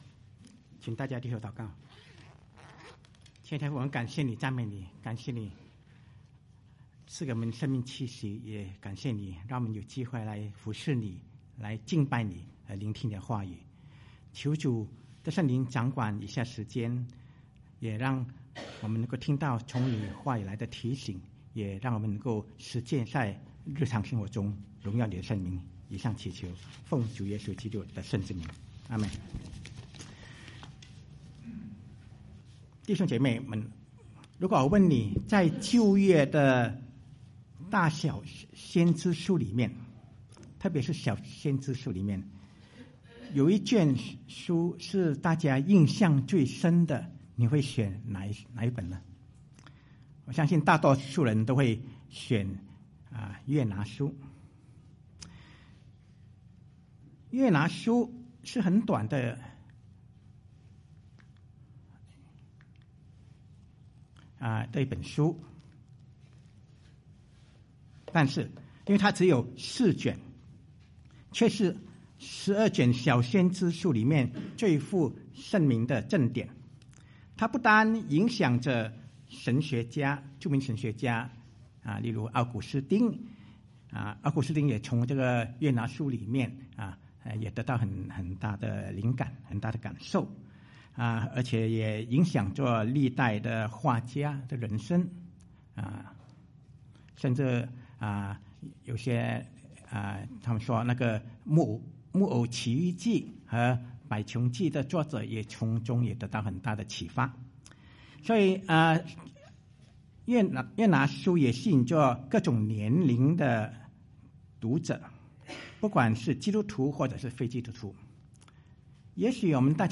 Sermons | Fraser Lands Church